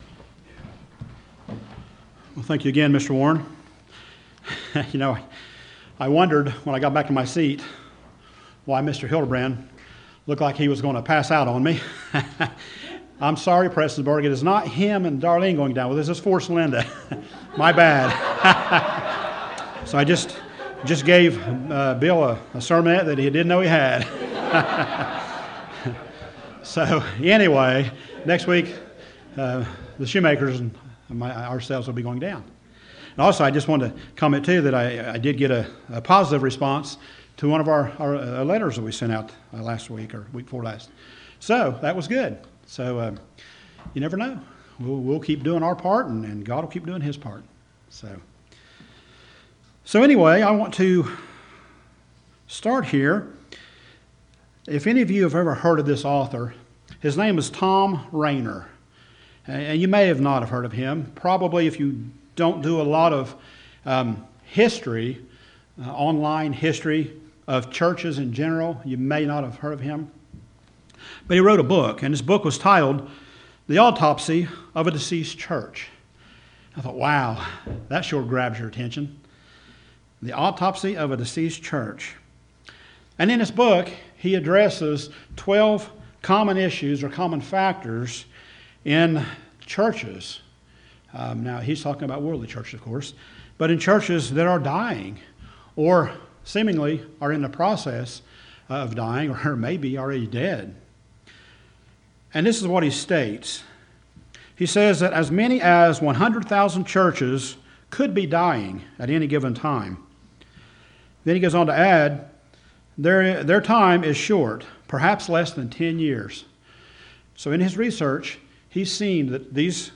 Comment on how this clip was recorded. Given in Portsmouth, OH Paintsville, KY